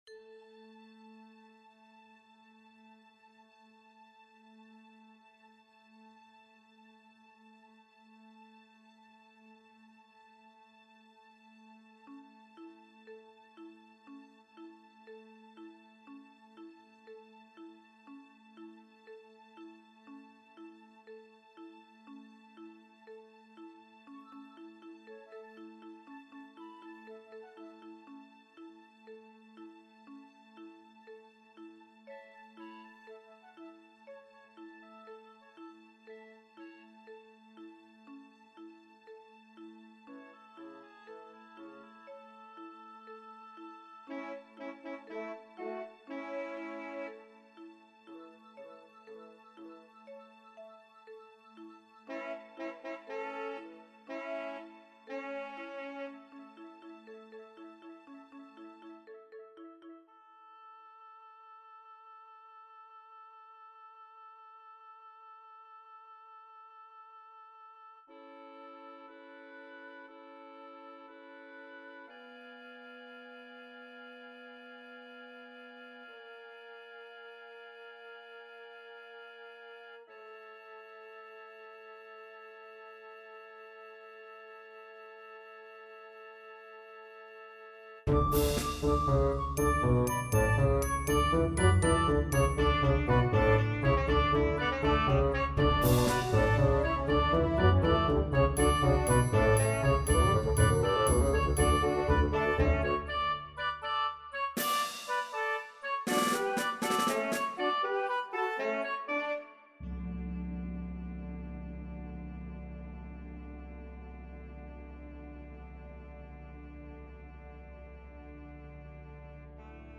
Het neemt je mee in een droom waarin je langs twee bijzondere planeten reist. De eerste planeet staat in het teken van vreugde: kleurrijk, speels en levendig. De tweede planeet ademt rust: kalm, zacht en vredig. Het stuk begint met een zacht ostinato-ritme op de marimba, als de subtiele tikjes van de natuur die je langzaam in slaap wiegen. Aan het einde wordt de muziek bijna fluisterzacht, alsof de droom langzaam vervaagt en je terugkeert naar de werkelijkheid.